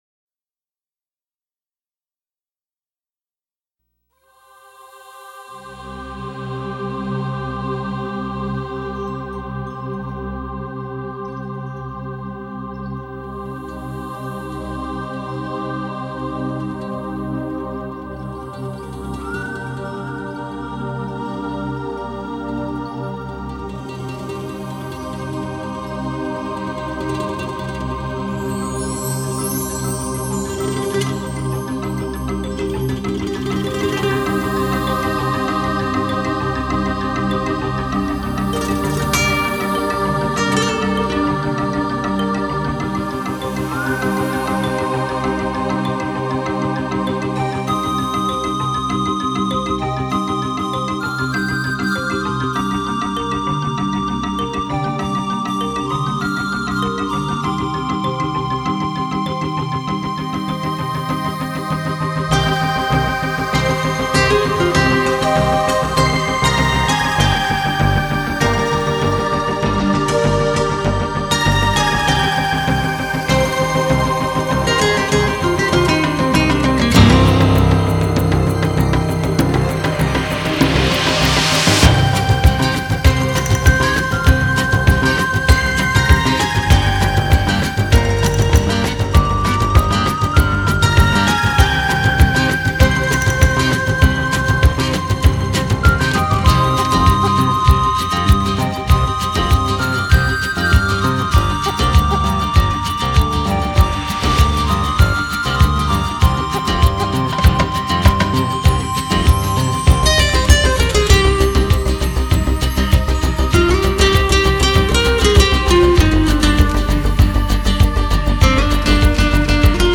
层出不穷的敲击、时尚的节奏韵律，淋漓尽致的色彩演绎